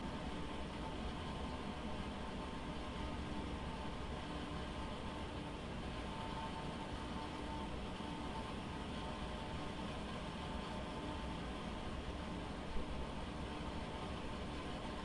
机器hum1
描述：机器的嗡嗡声（实际上是来自于一台数字β机！）
Tag: 嗡嗡声 机器 呼呼